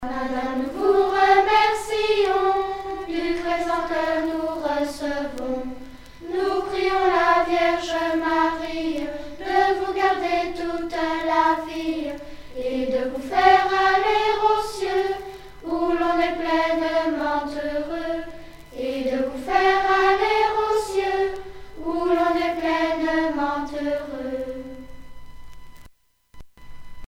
Chanson
Pièce musicale éditée